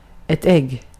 Ääntäminen
Ääntäminen Tuntematon aksentti: IPA: /ɛɡ/ Haettu sana löytyi näillä lähdekielillä: ruotsi Käännös Substantiivit 1. muna 2. kananmuna Muut/tuntemattomat 3. munasolu Artikkeli: ett .